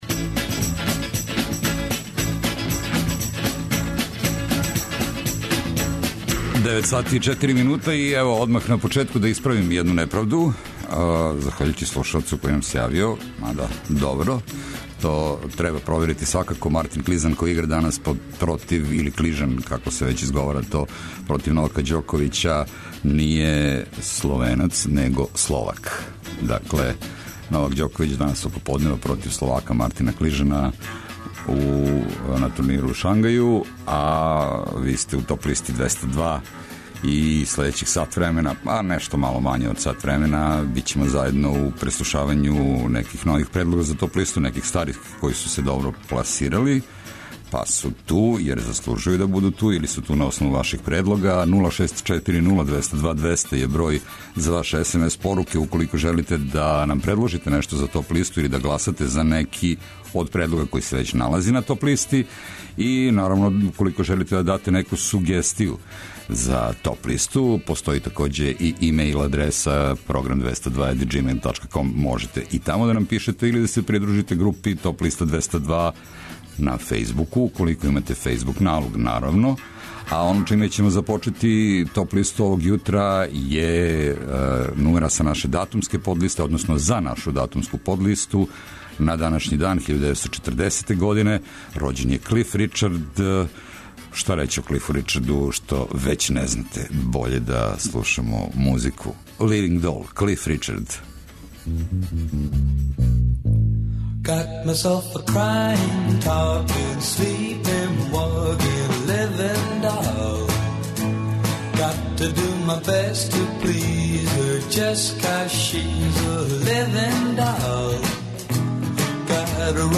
Најавићемо актуелне концерте у овом месецу, подсетићемо се шта се битно десило у историји музике у периоду од 12. до 16. октобра. Ту су и неизбежне подлисте лектире, обрада, домаћег и страног рока, филмске и инструменталне музике, попа, етно музике, блуза и џеза, као и класичне музике.